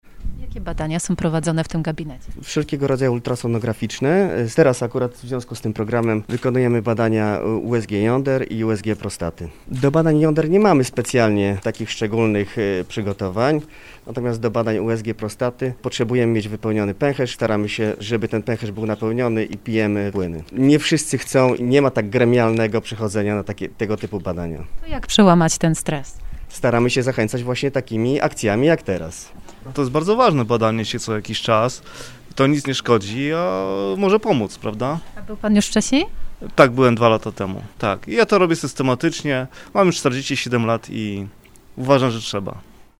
04_radiolog-i-pacjent.mp3